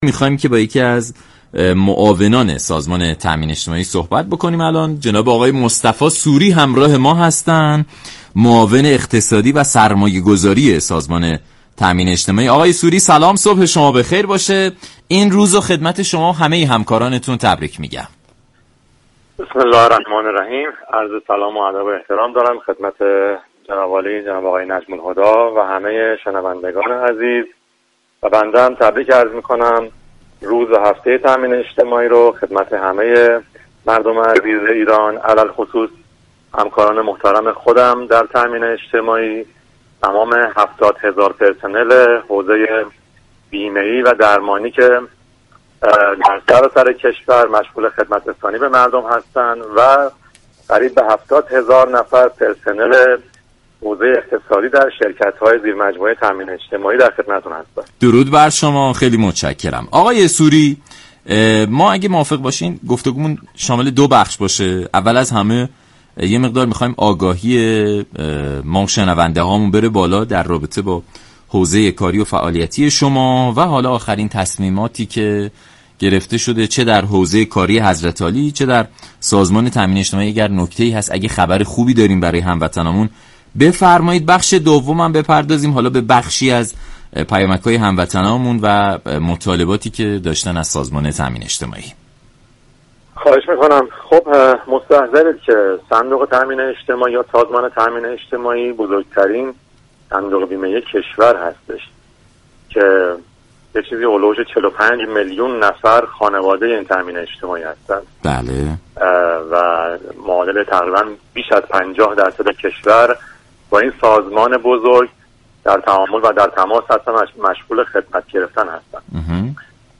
به گزارش شبكه رادیویی ایران، مصطفی سوری معاون اقتصادی و سرمایه گذاری سازمان تامین اجتماعی در برنامه سلام صبح بخیر به پیامك های ارسالی سازمان تامین اجتماعی به بیمه شدگان اشاره كرد وگفت: برای بیمه شده های خویش فرما و اختیاری، بسته به شعب و استان هایی كه كار را انجام می دهند، معمولا پیامك های پرداخت بیمه ارسال می شود، در تلاش هستیم؛ این اقدام شكل منظم تری به خود گیرد.